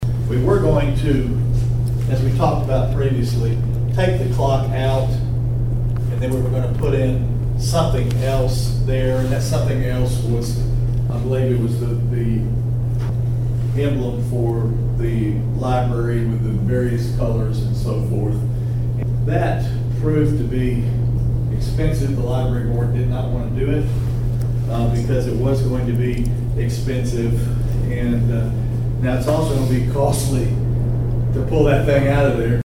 Mayor Belote told us other options the city discussed.